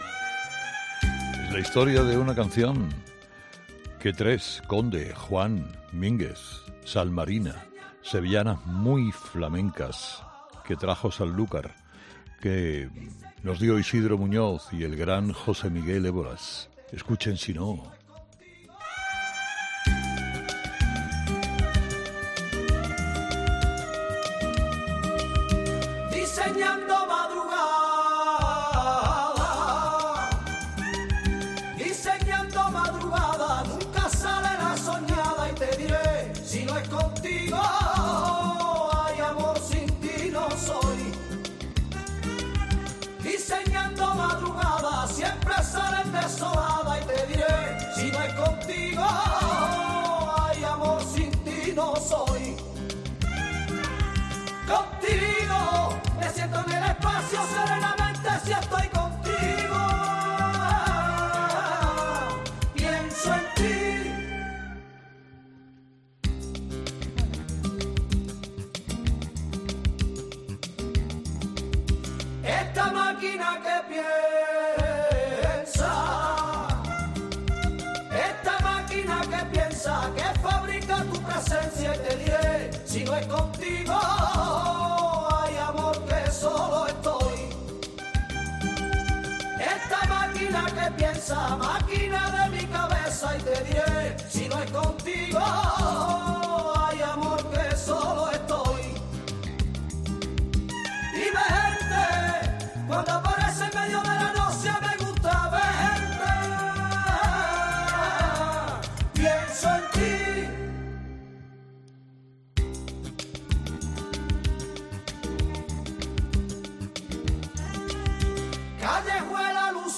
sevillanas muy flamencas